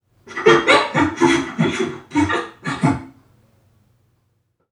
NPC_Creatures_Vocalisations_Robothead [83].wav